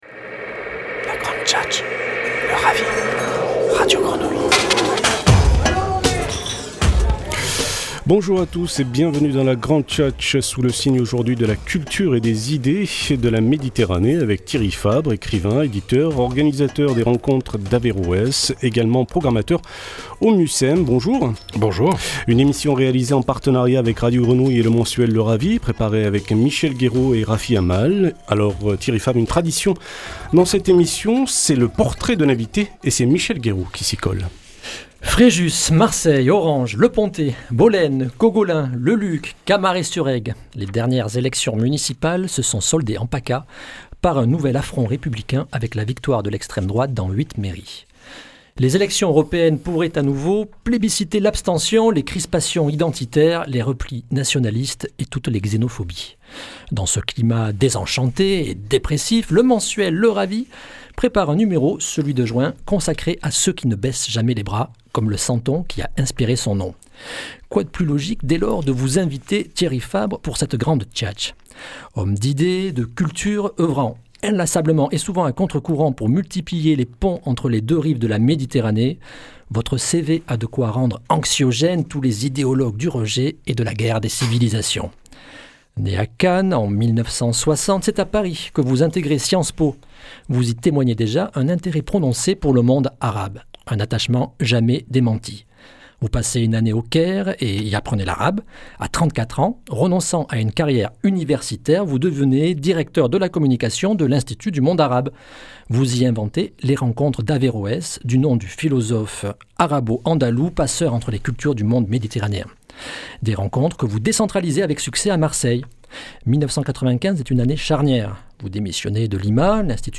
Entretien en partenariat avec Radio Grenouille